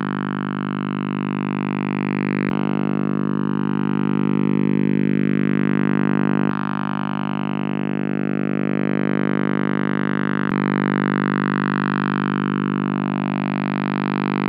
- Russian OTH radar Contayner with 50 sps
rus-othr-cont.wav